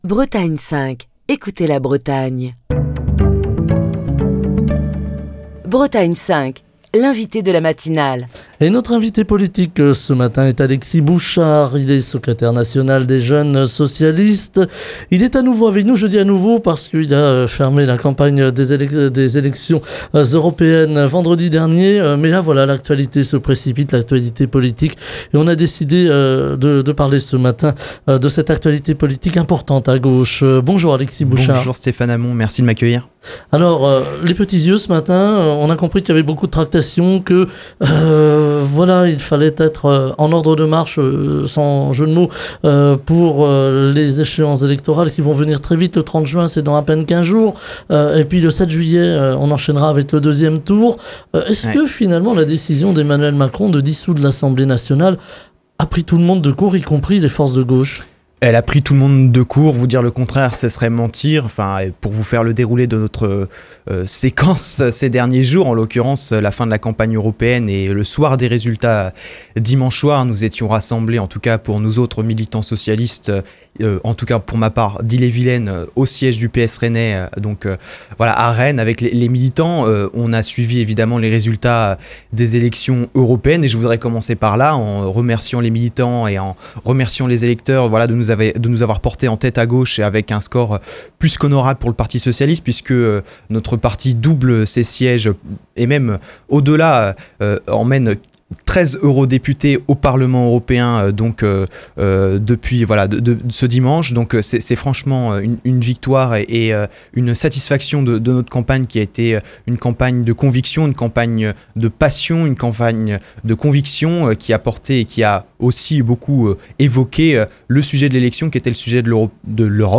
Invité politique de la matinale de Bretagne 5